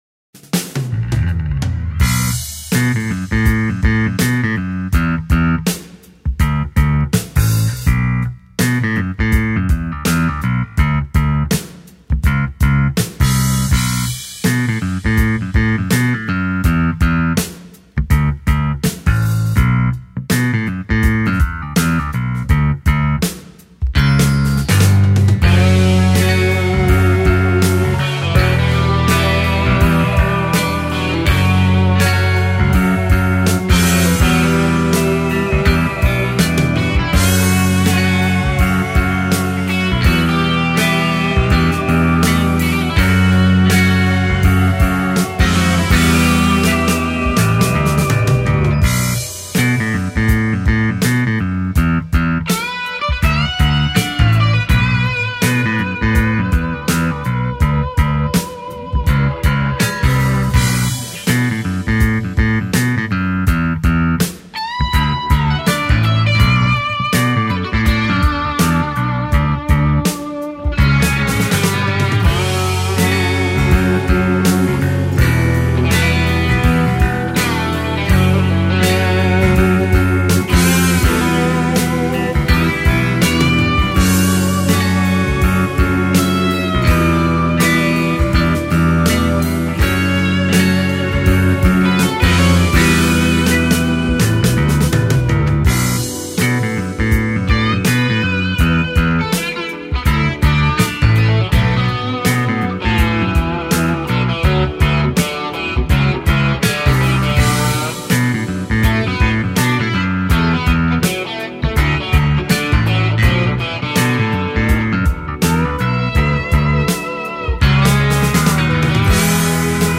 DES SONS DE BASSE BRILLANTS ET DYNAMIQUES
Que tu joues de la funk, de la pop ou du rock